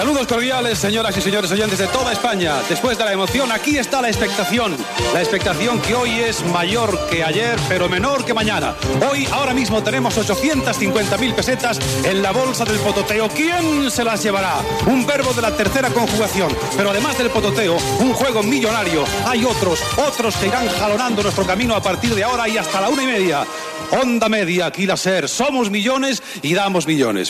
Concurs "El pototeo"
Entreteniment